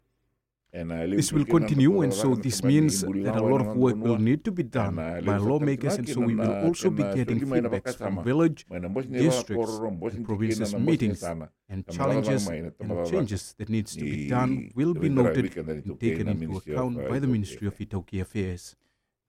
Prime Minister Sitiveni Rabuka, while speaking on Radio Fiji One’s “Na Noda Paraiminisita” program, stressed this move.